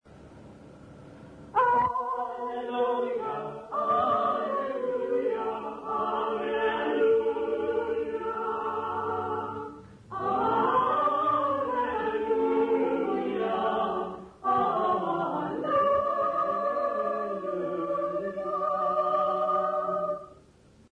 Intshanga church music workshop participants
Folk music South Africa
Hymns, Zulu South Africa
field recordings
Unaccompanied church hymn.